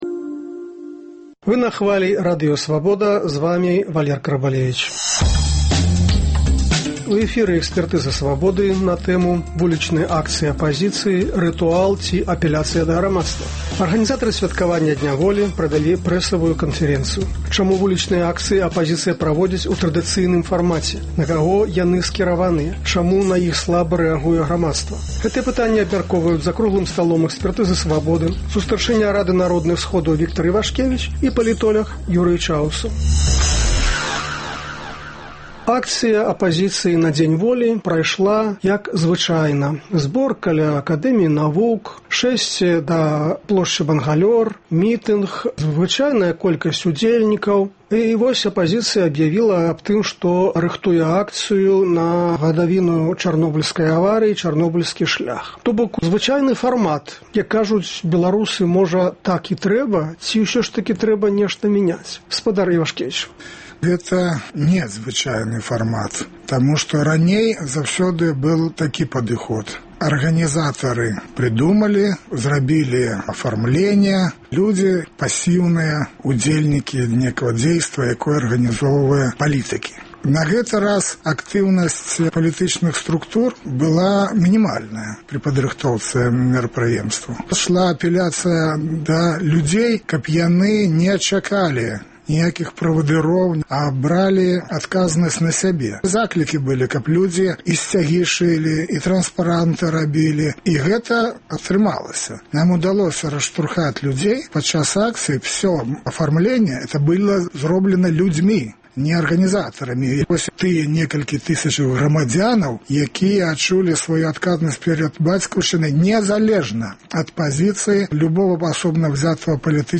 Чаму на іх слаба рэагуе грамадзтва? Гэтыя пытаньні абмяркоўваюць за круглым сталом